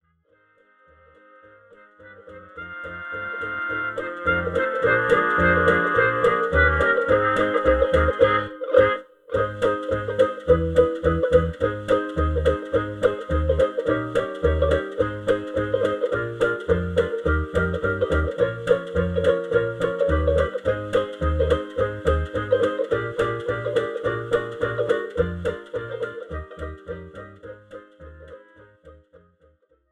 • Without Backing Vocals
• No Fade